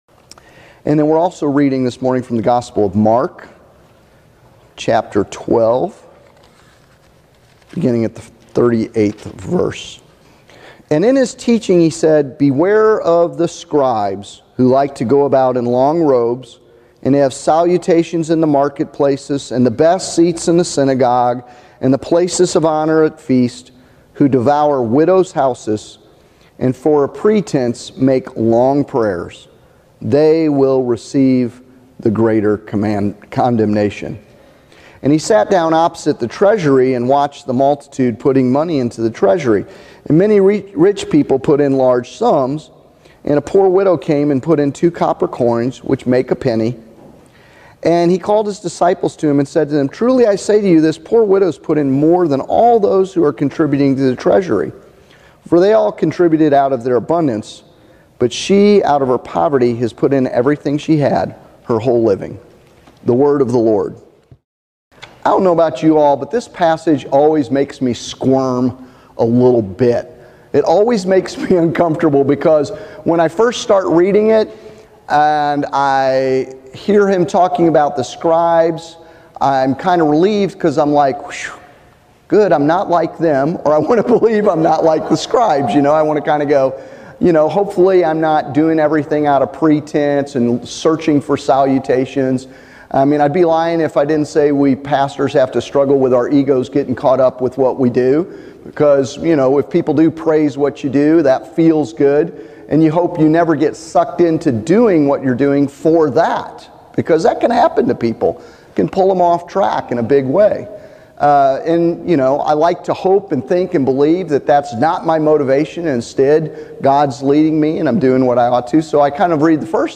The text for this sermon is Mark 12:38-44, Story of the Widow’s Mite.